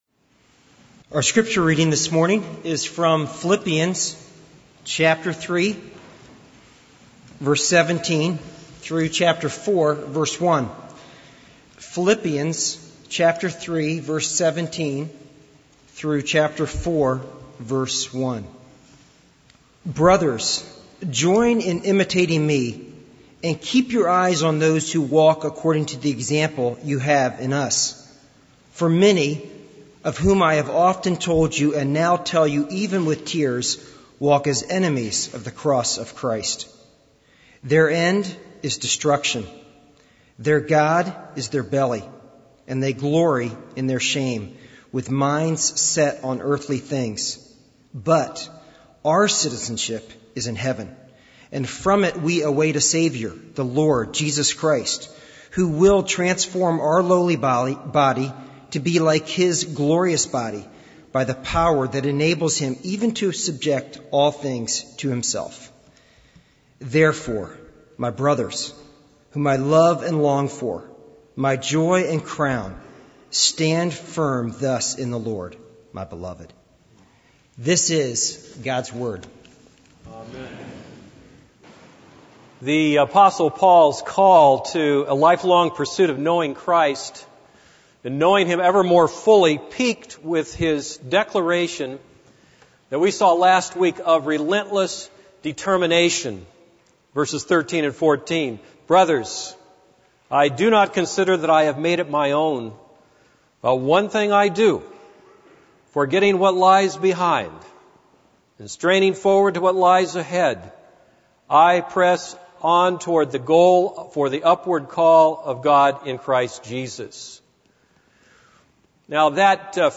This is a sermon on Philippians 3:17-4:1.